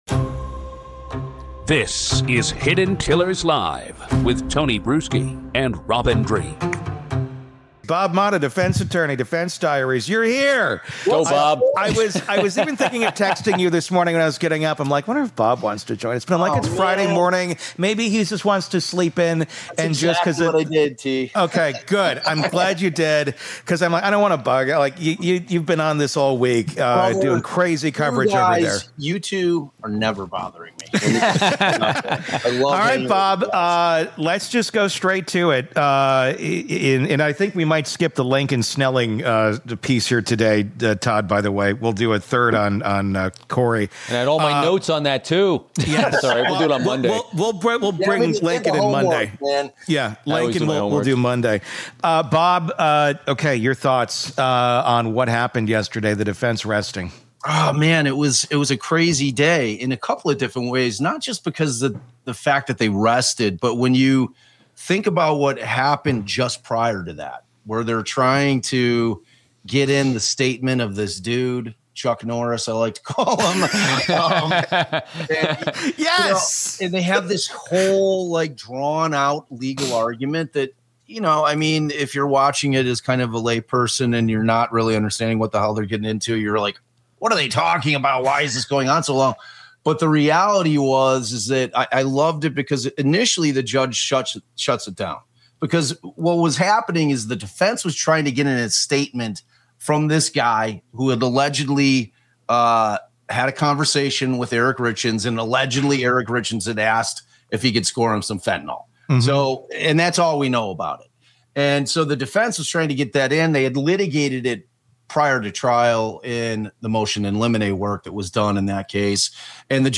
It's happening live.